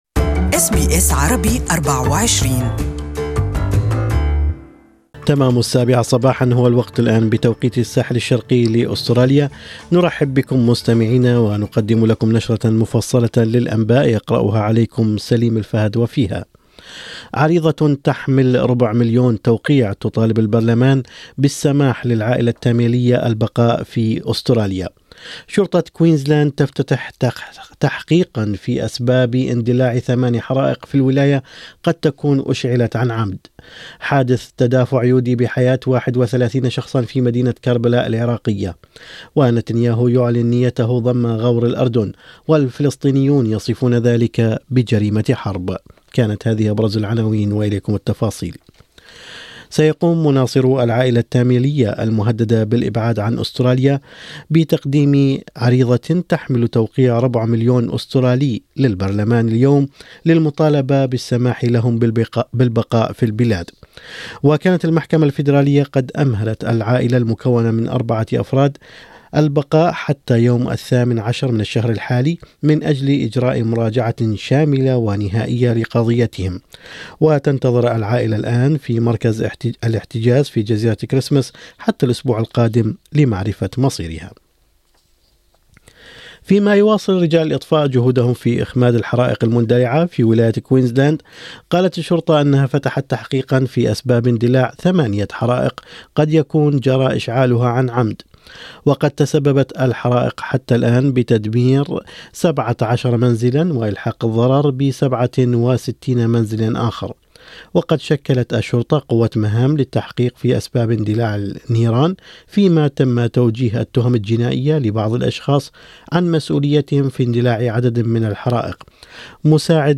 Morning News: Tamil family supporters to deliver petition to Parliament